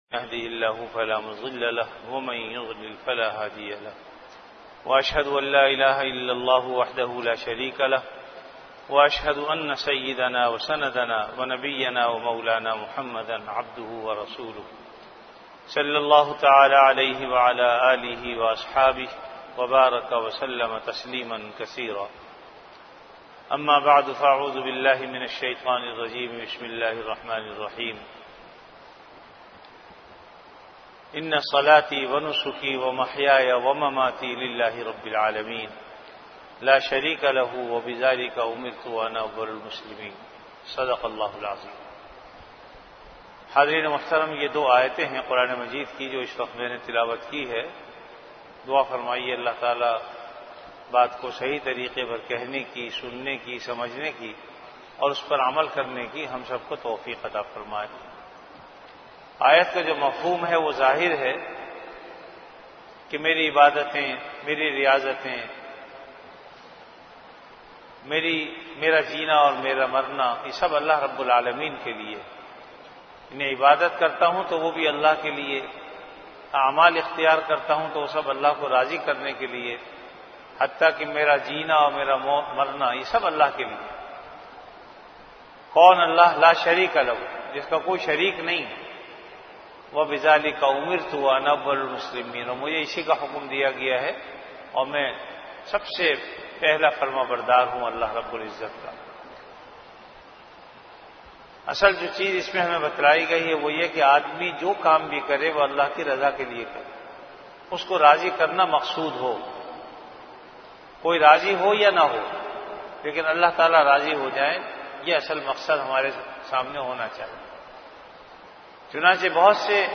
An Islamic audio bayan
Delivered at Jamia Masjid Bait-ul-Mukkaram, Karachi.